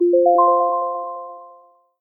accomplished alert amazing application awesome bells blip bonus sound effect free sound royalty free Animals